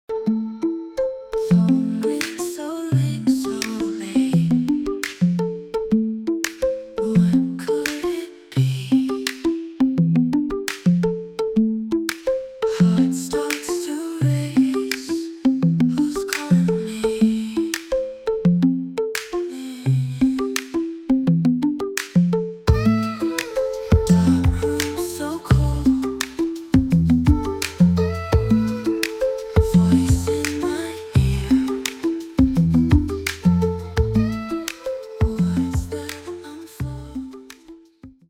Short version of the song, full version after purchase.
An incredible Pop song, creative and inspiring.